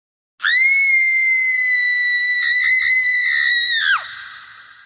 grito de terror mulher Meme Sound Effect
grito de terror mulher.mp3